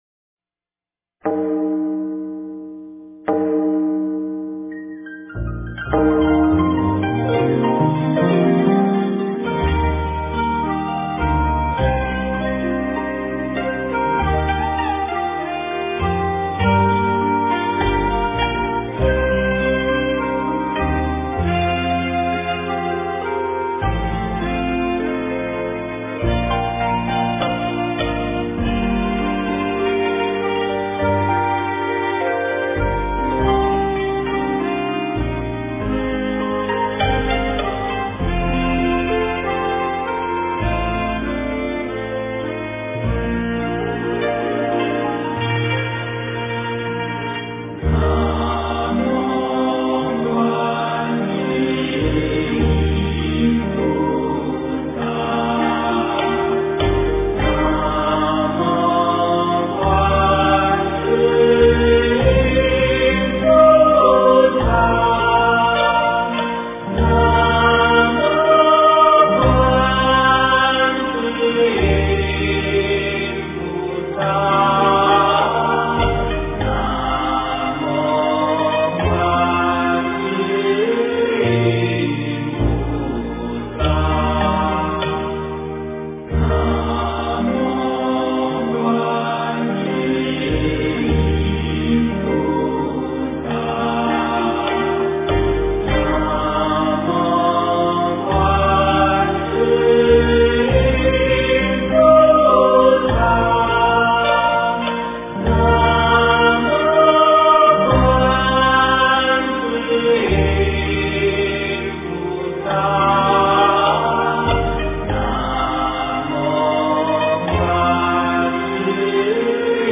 南无观世音菩萨--四音调唱赞 经忏 南无观世音菩萨--四音调唱赞 点我： 标签: 佛音 经忏 佛教音乐 返回列表 上一篇： 药师灌顶真言--如是我闻 下一篇： 大方广佛华严经华严普贤行愿忏-下--僧团 相关文章 大悲咒.心经.七佛灭罪真言.补阙真言.赞--圆光佛学院众法师 大悲咒.心经.七佛灭罪真言.补阙真言.赞--圆光佛学院众法师...